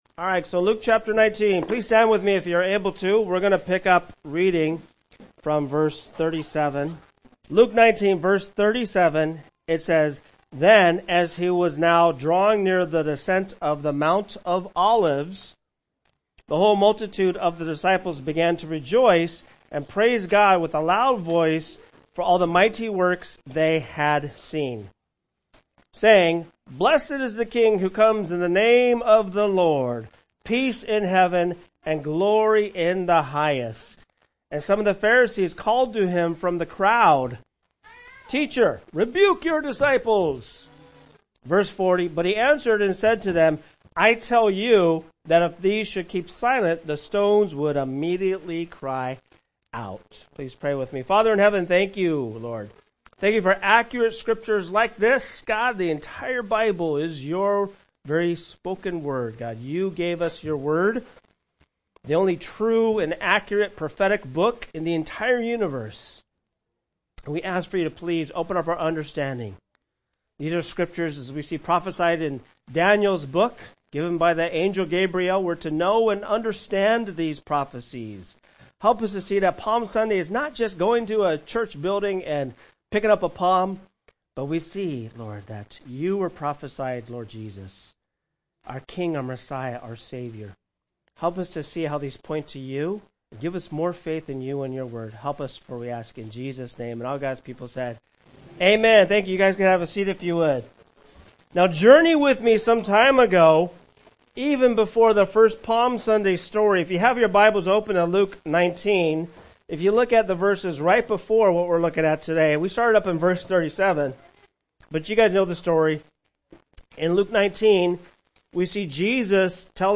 Watch or listen and discover the Palm Sunday meaning here in this Palm Sunday sermon.